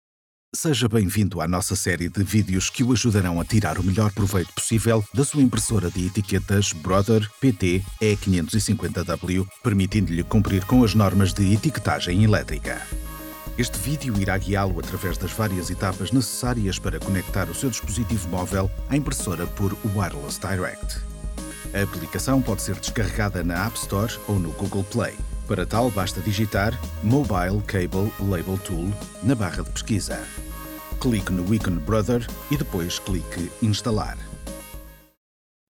Portuguese Professional Voice Actor.
Sprechprobe: Industrie (Muttersprache):
Baritone male voice with pleasant, warm, calm, smooth and vibrant tone.